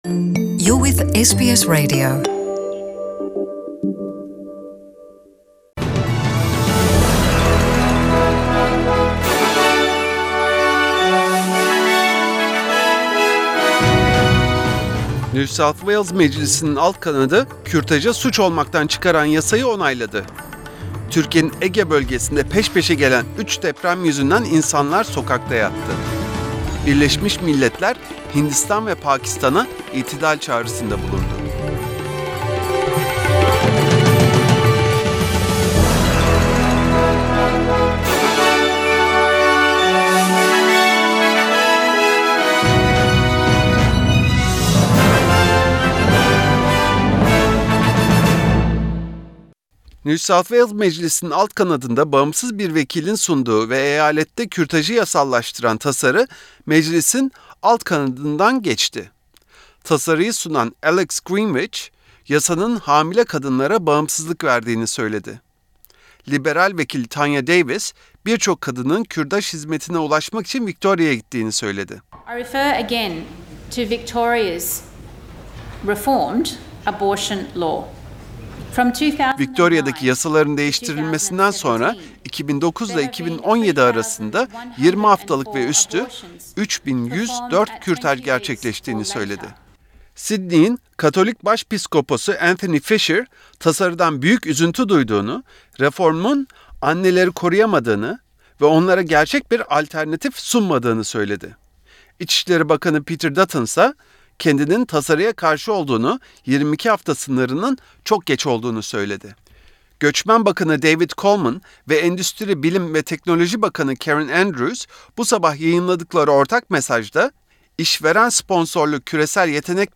SBS Radyosu Türkçe Programı'ndan Avustralya, Türkiye ve dünyadan haberler. Başlıklar *New South Wales meclisinin alt kanadı, kürtajı suç olmaktan çıkaran yasayı onayladı *Türkiyenin Ege bölgesinde peşpeşe gelen 3 deprem yüzünden insanlar sokaklarda yattı *Birleşmiş milletler hintistan ve pakistana itidal çağrısında bulundu.